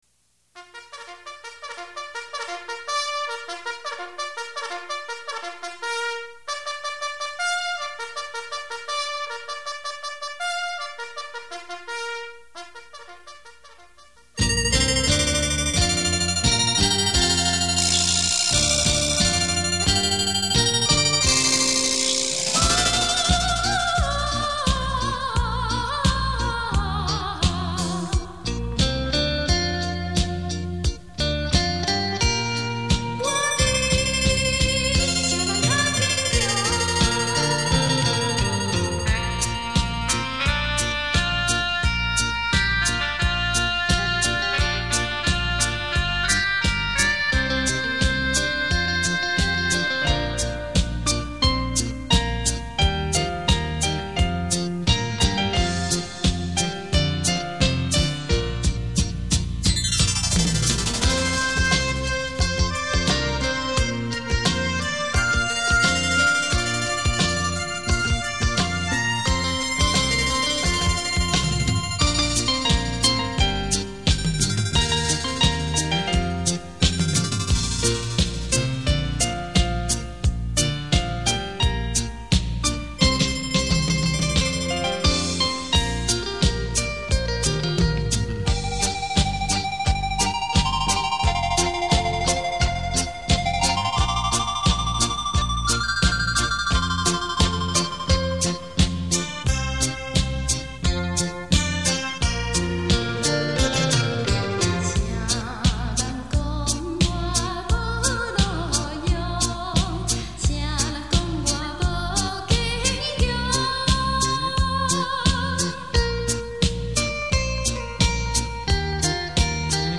超時空特殊效果音樂  百萬名琴魅力大出擊
電聲發燒珍品.